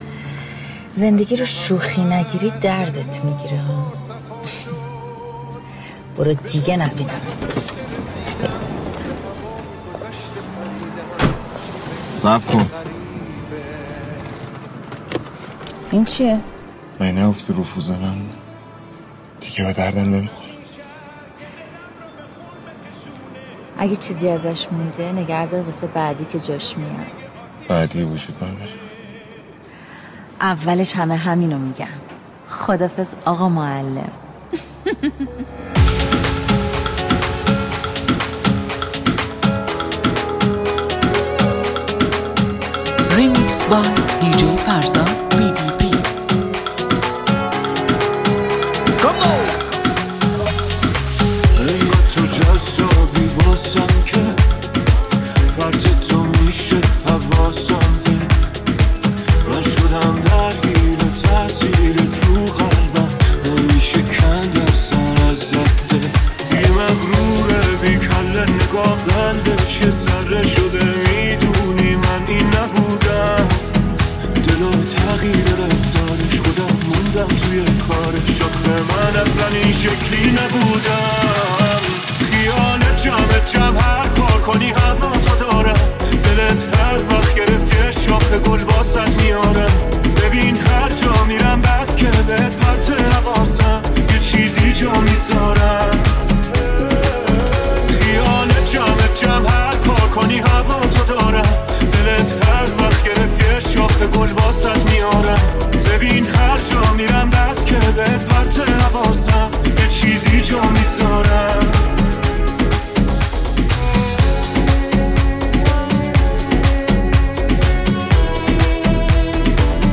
Club Remix